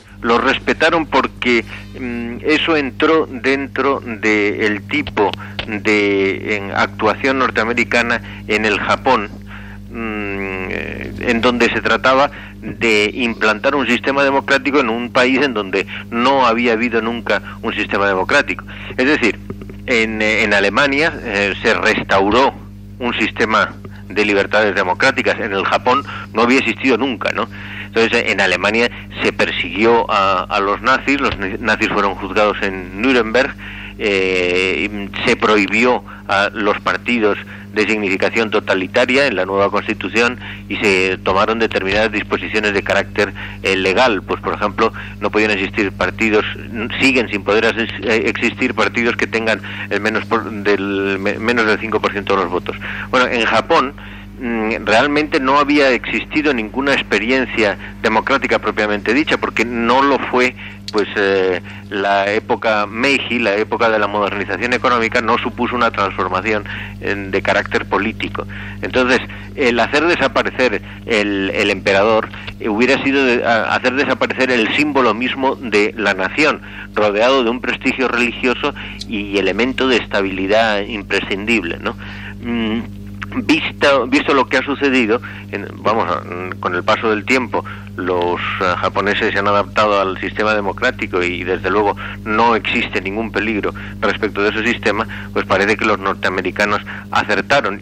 El catedràtic d'Història Contemporània Javier Tusell parla de la figura de l'emperador japonès Hirohito, que havia mort l'1 de gener de 1989
Informatiu